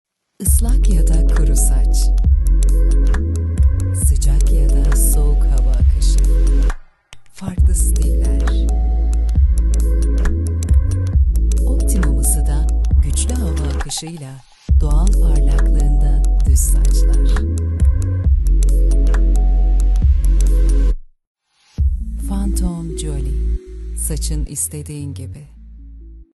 Female
Authoritative , Character , Confident , Corporate , Friendly , Natural , Reassuring , Smooth , Warm , Versatile